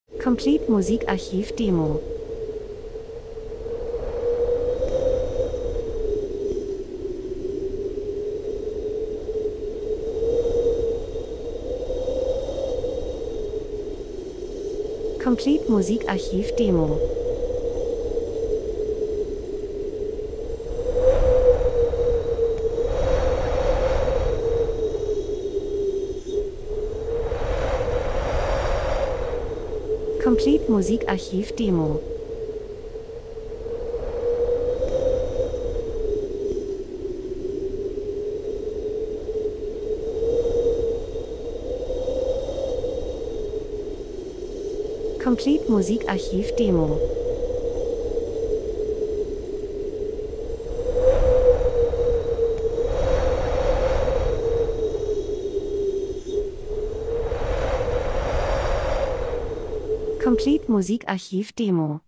Winter -Sturm Wind Schnee aufbrausend kalt 01:02